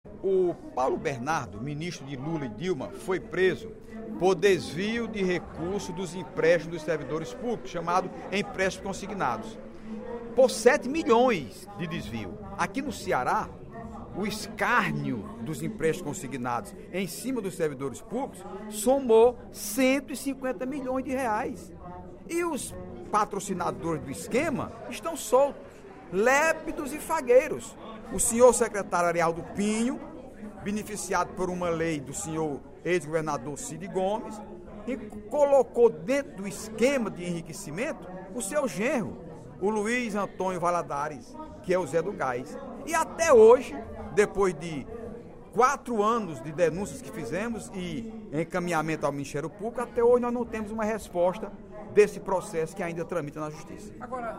Dep. Heitor Férrer (PSB) Agência de Notícias da ALCE